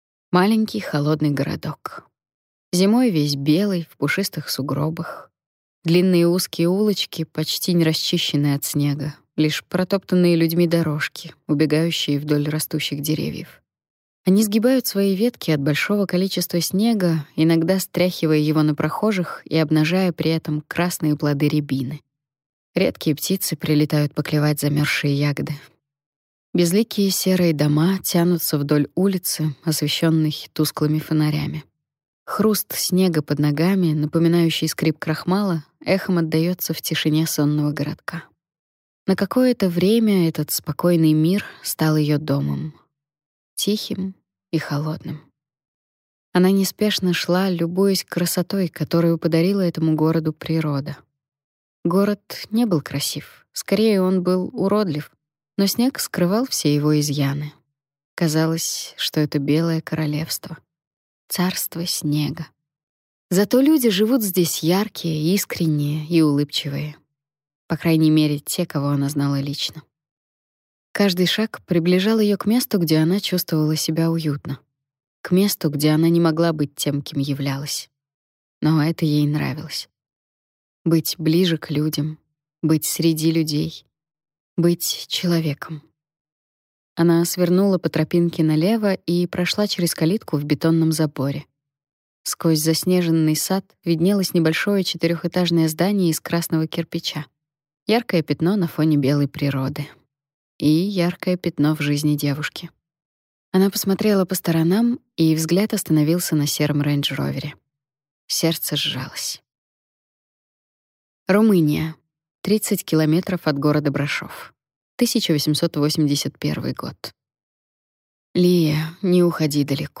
Аудиокнига Легенда о вампирах. Диаблери | Библиотека аудиокниг